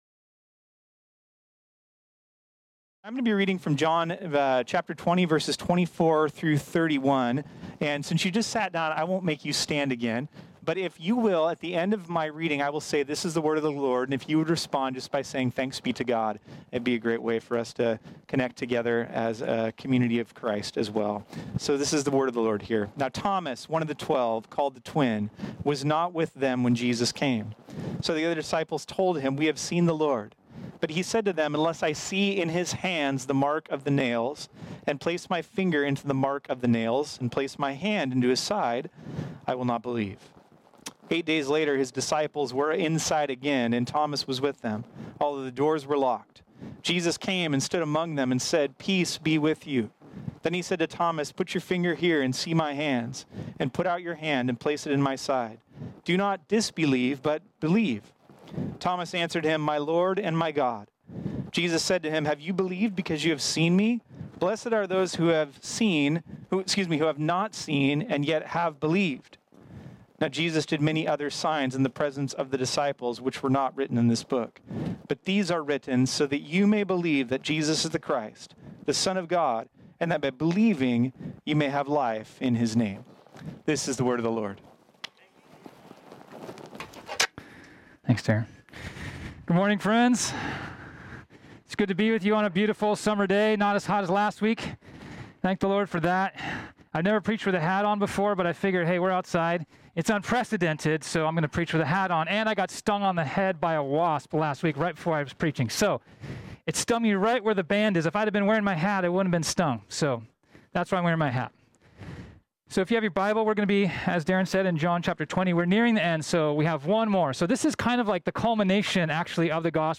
This sermon was originally preached on Sunday, August 23, 2020.